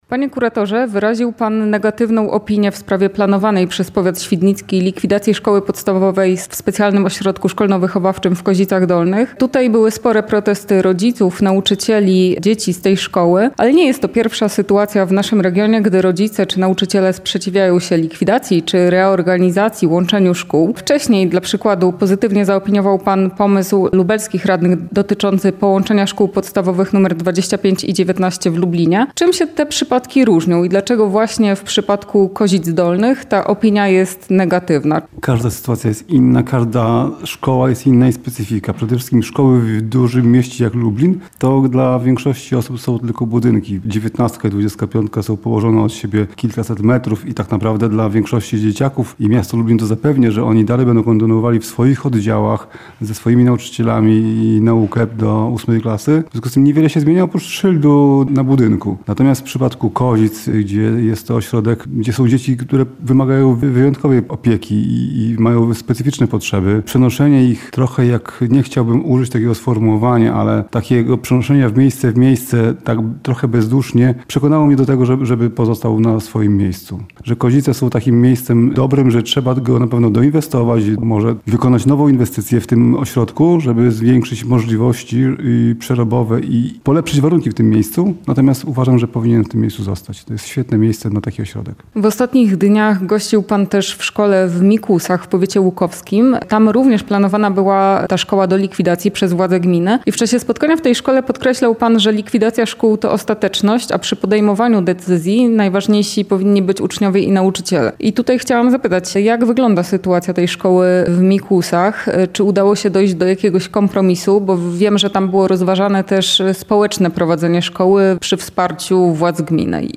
Rozmowa z lubelskim kuratorem oświaty Tomaszem Szabłowskim